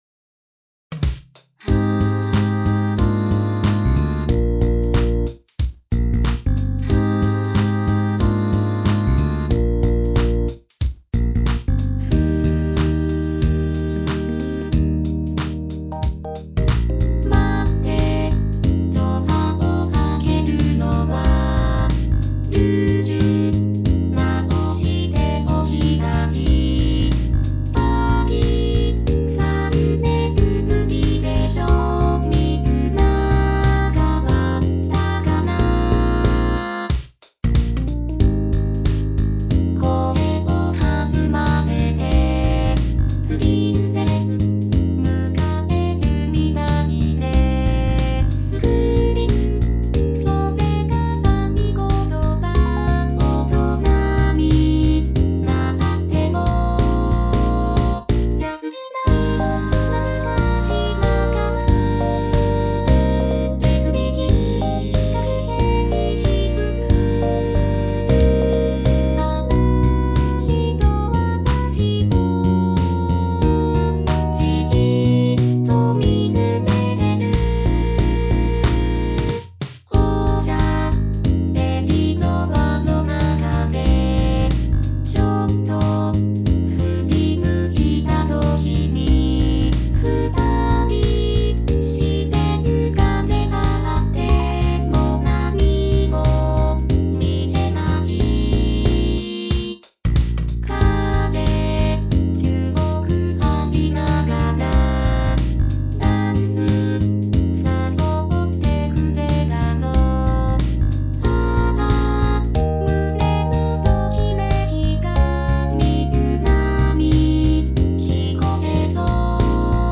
（UTAU音源メンバー）